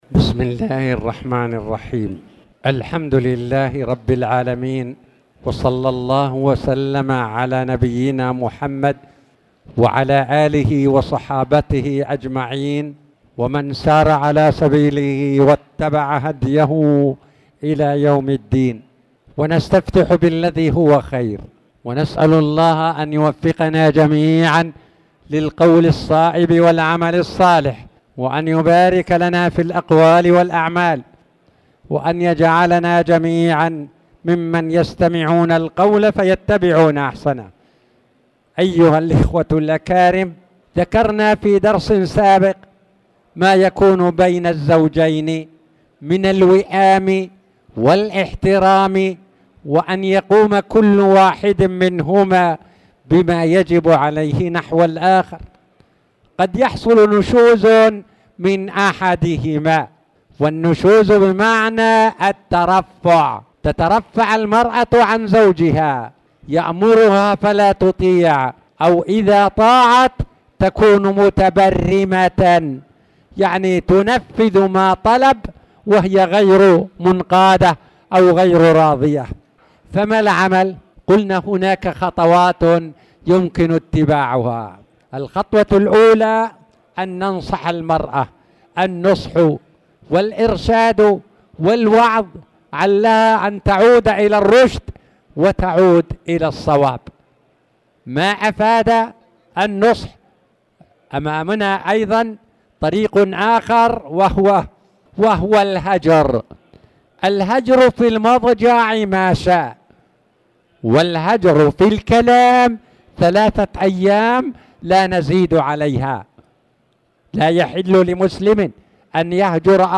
تاريخ النشر ٩ جمادى الآخرة ١٤٣٨ هـ المكان: المسجد الحرام الشيخ